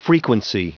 Prononciation du mot frequency en anglais (fichier audio)
Prononciation du mot : frequency